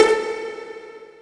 voiTTE64025voicesyn-A.wav